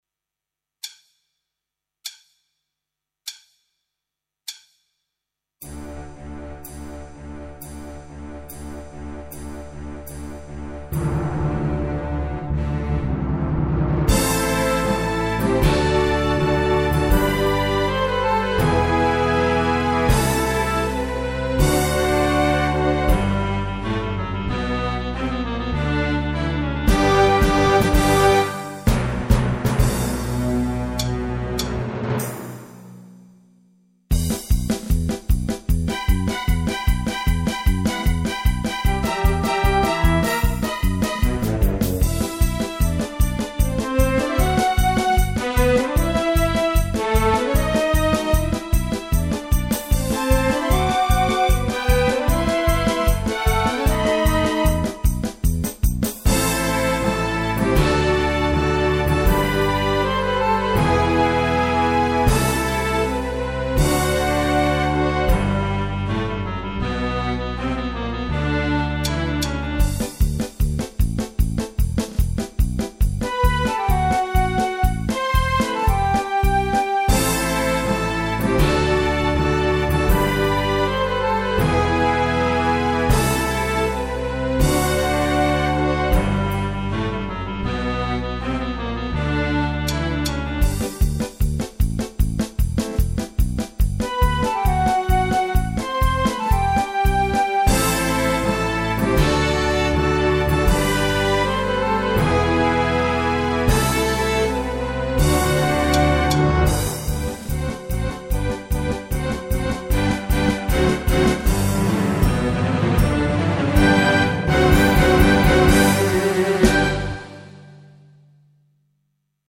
per Orchestra e Fisarmonica